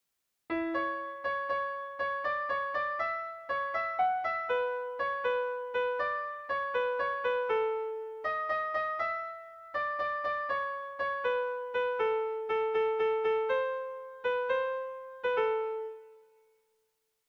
Seiko berezia, 3 puntuz (hg) / Hiru puntuko berezia (ip)
ABD